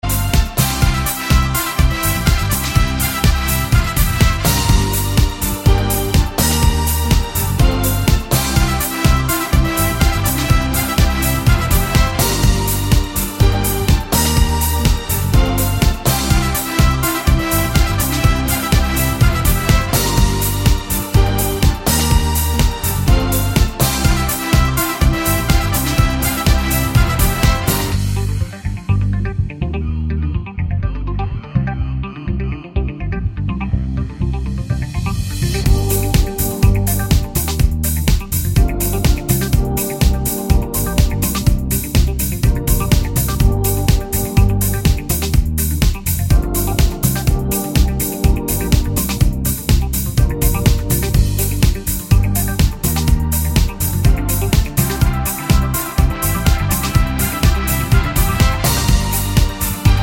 no Backing Vocals Dance 3:27 Buy £1.50